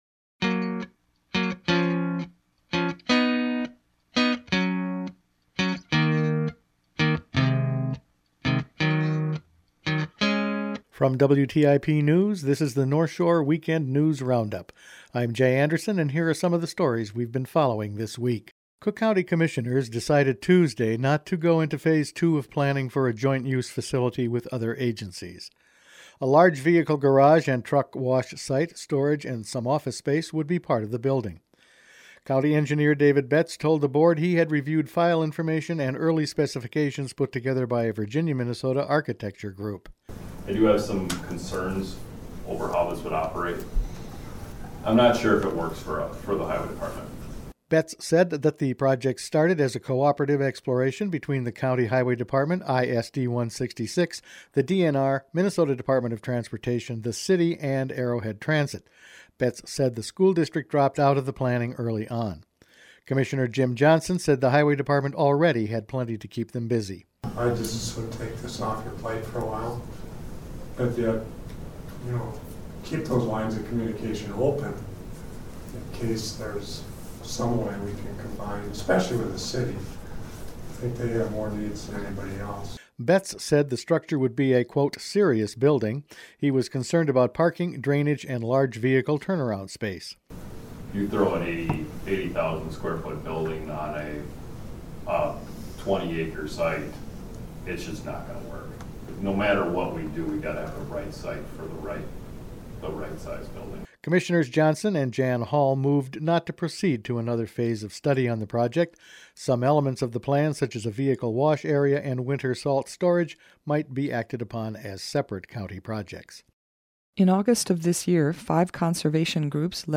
Attachment Size WrapFinalCut_111910.mp3 18.6 MB Each weekend WTIP news produces a round up of the news stories they’ve been following this week. Lead levels in lakes, a major grant for the Poplar River and a go-it-alone decision on a new vehicle garage, were some of the headlines.